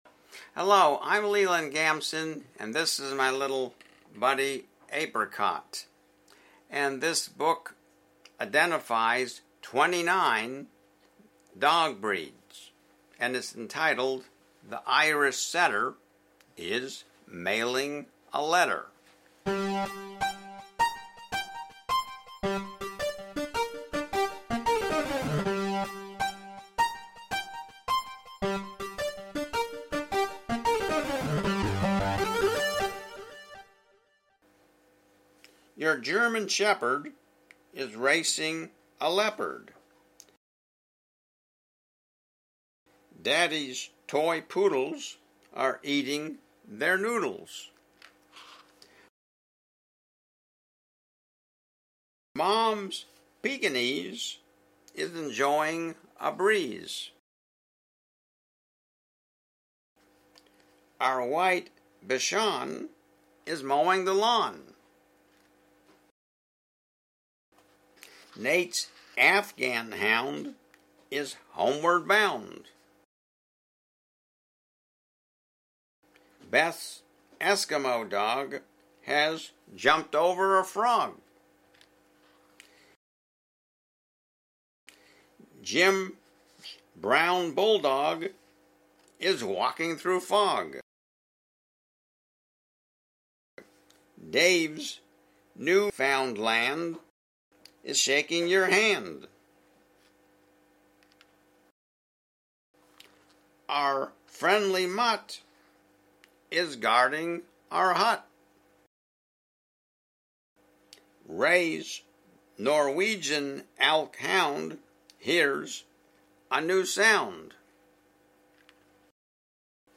Reading THE IRISH SETTER IS MAILING A LETTER